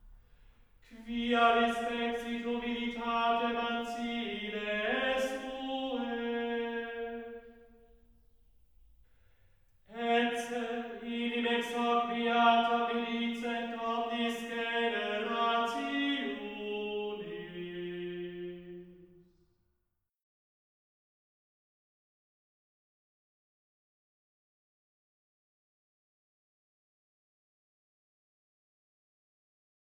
Cembalo, Bibelregal, Zuberbier-Orgel Dudensen, Gesang